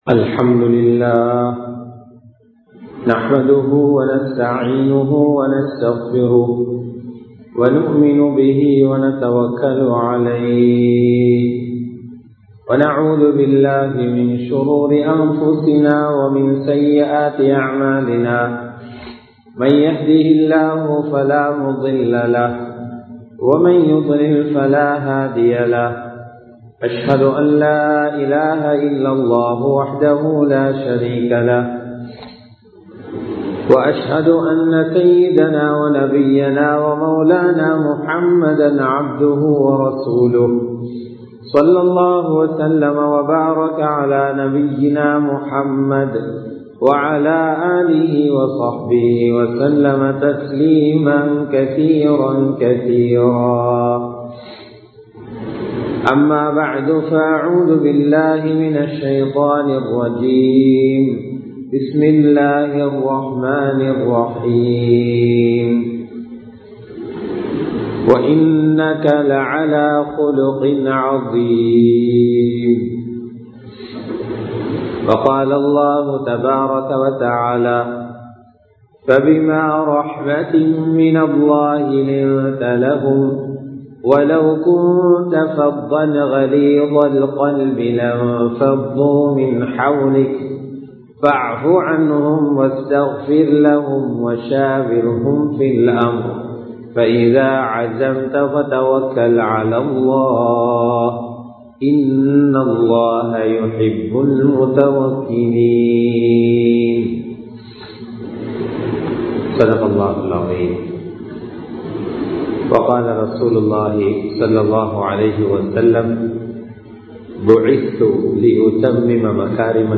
நபி(ஸல்) அவர்களும் நற்குணமும் | Audio Bayans | All Ceylon Muslim Youth Community | Addalaichenai